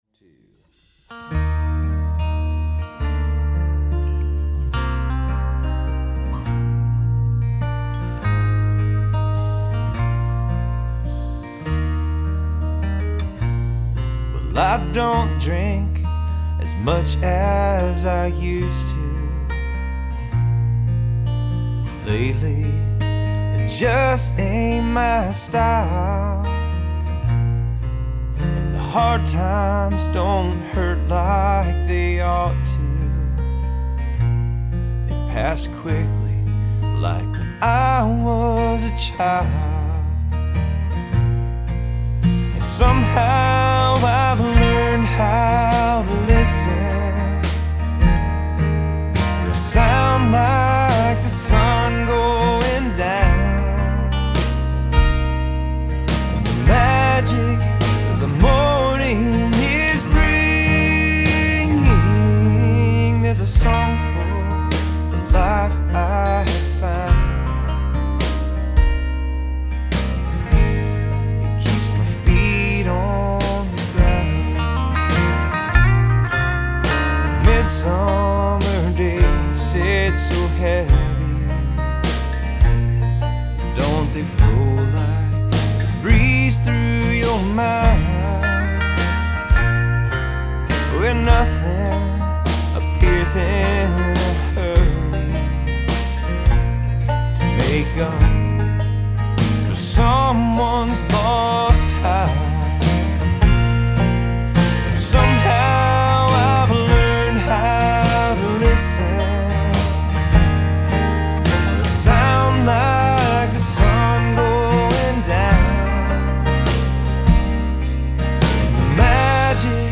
I started with acoustic guitar.